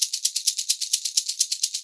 Index of /90_sSampleCDs/USB Soundscan vol.36 - Percussion Loops [AKAI] 1CD/Partition B/22-130SHAKER